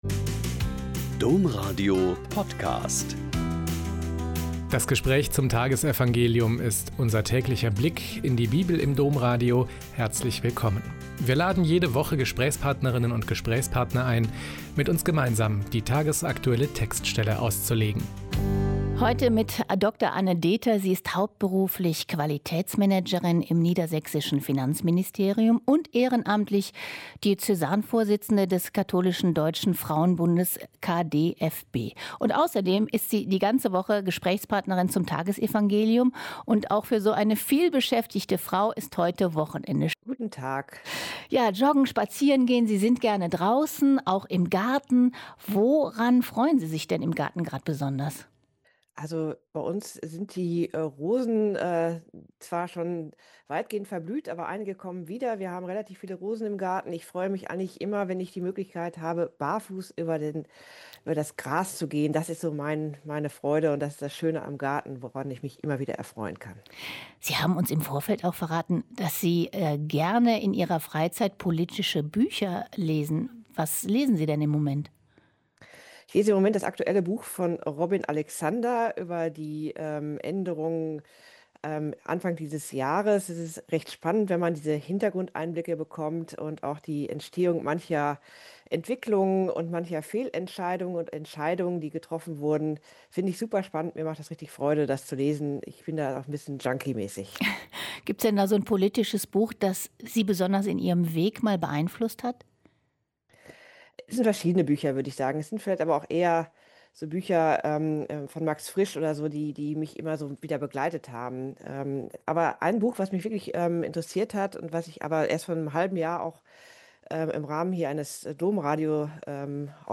Mt 12,14-21 - Gespräch